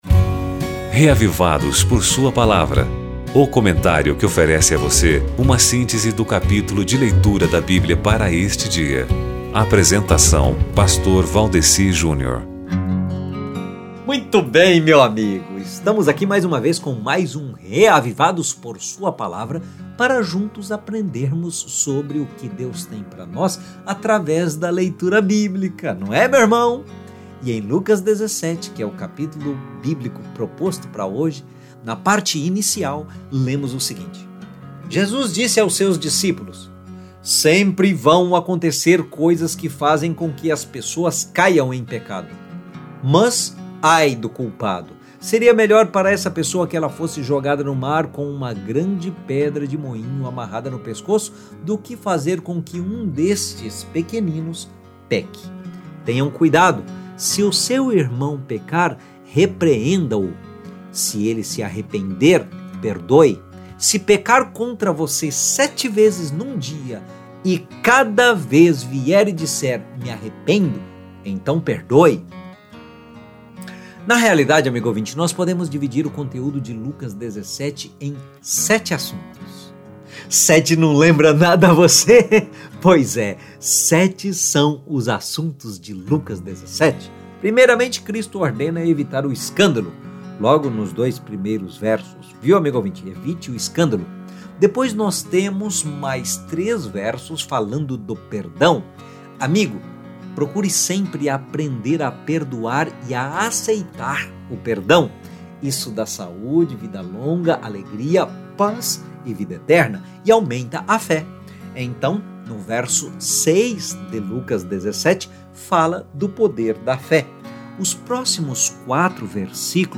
testemunho